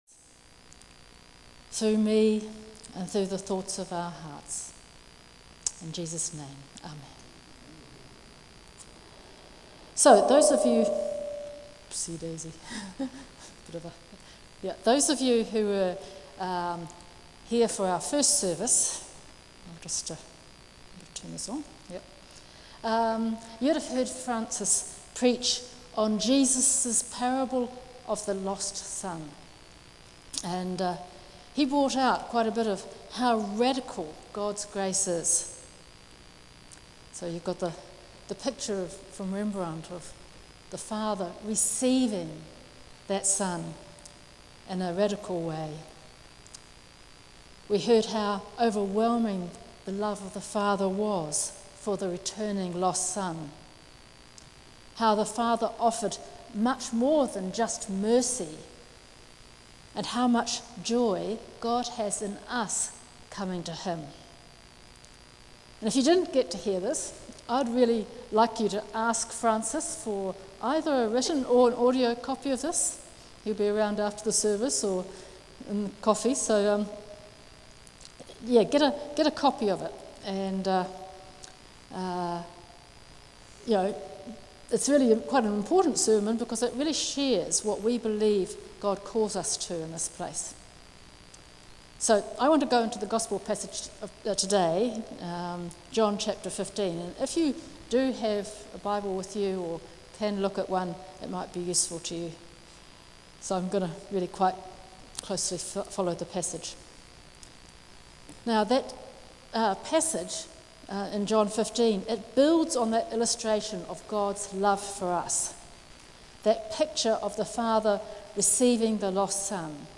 John 15:8-17 Service Type: Holy Communion Abiding in the vine